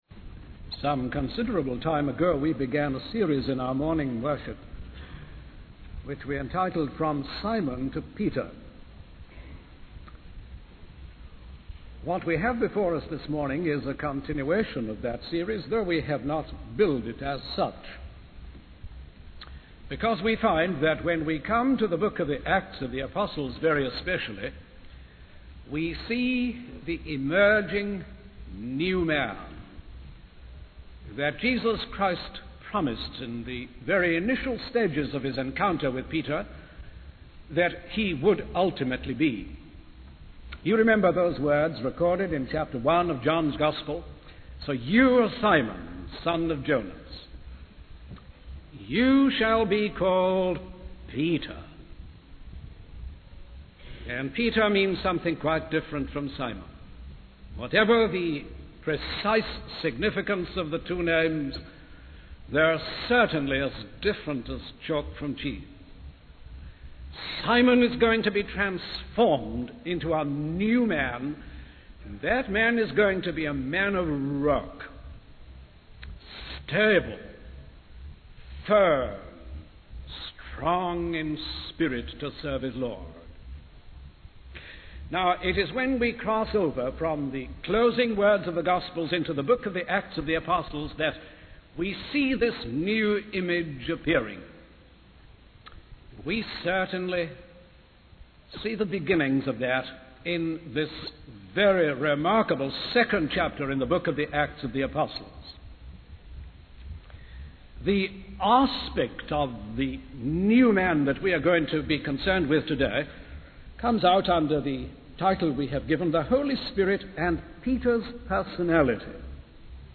In this sermon, the speaker focuses on the character of Peter and his journey from fear to boldness.